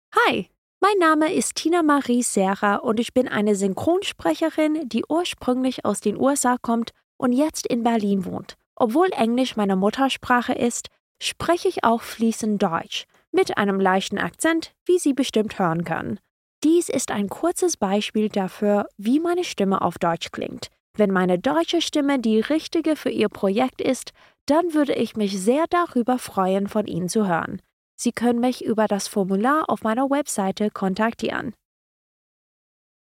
hell, fein, zart, sehr variabel
Tale (Erzählung)